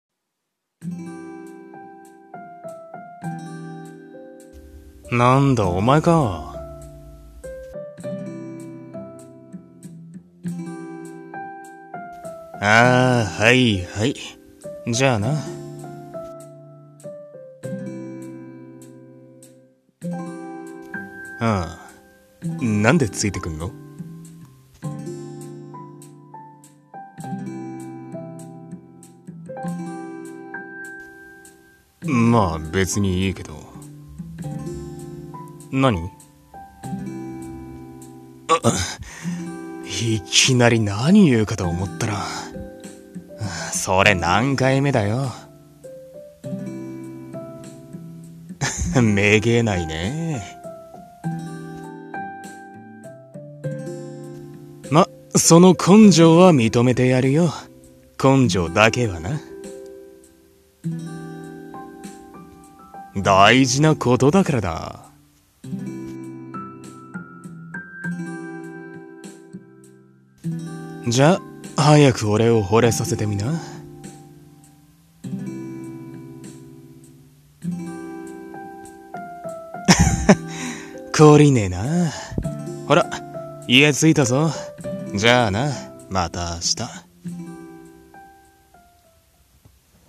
声劇 掛け合い】あざとい系女子の片想い